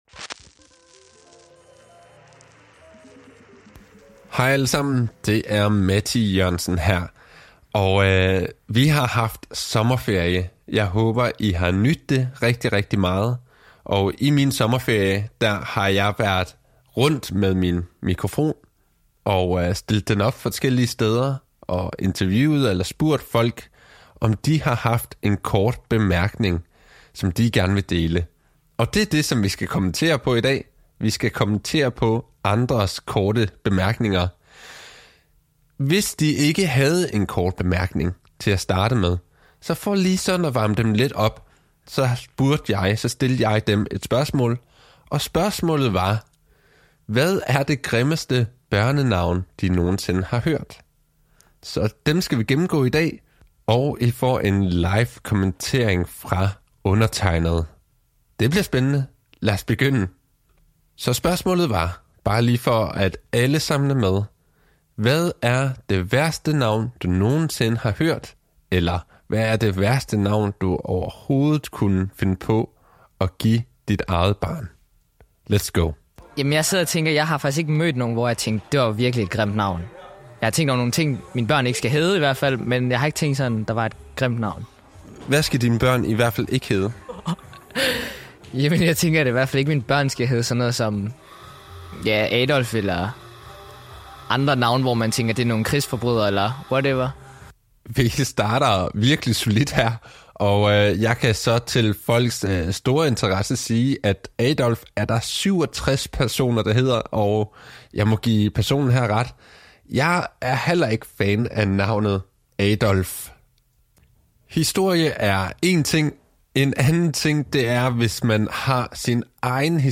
Sommerferien har ramt, og jeg har været ude i blandt menneskeskarerne og forhørt mig om de har en kort bemærkning? Som opvarmningsspørgsmål stillede jeg spørgsmålet: hvad er det værste navn nogensinde?